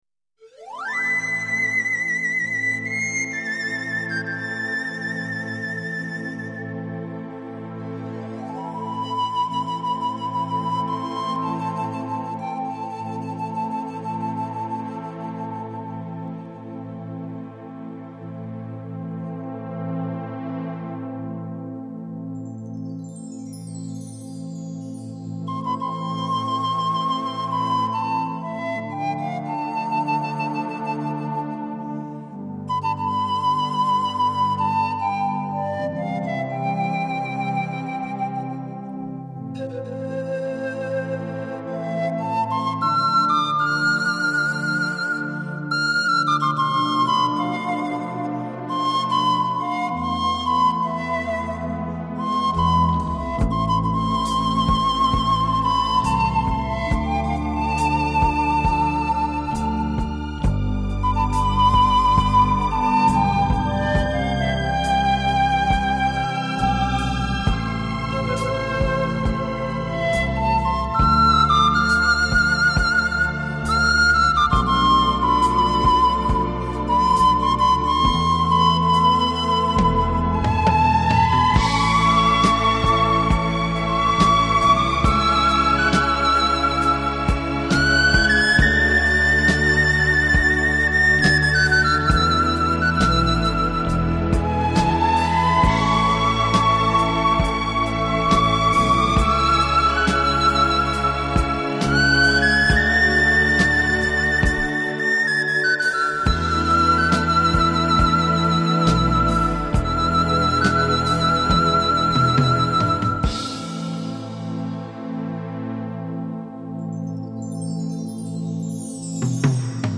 音乐除了延续其以往温暖抒情的风格外，音乐中更加入了大自然的百鸟鸣叫，听者犹如置身大森林中，感受美妙纯净的自然气息。
排箫